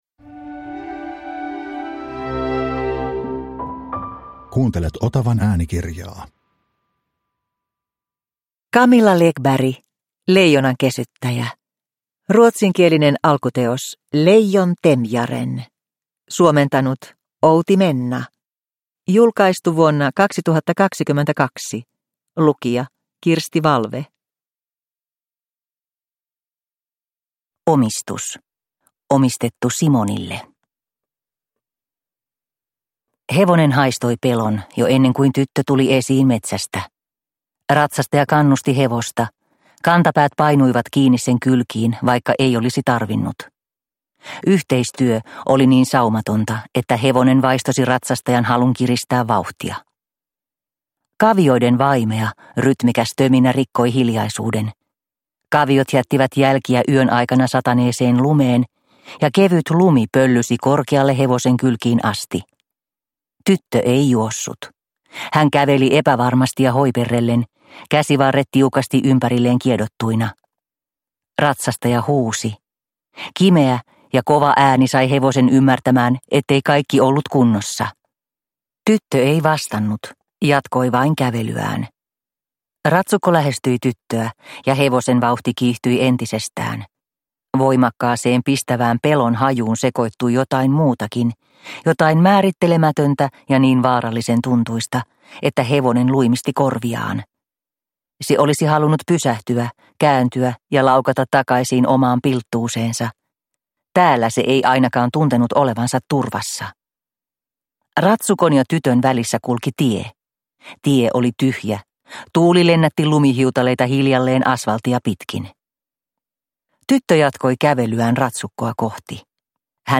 Leijonankesyttäjä – Ljudbok – Laddas ner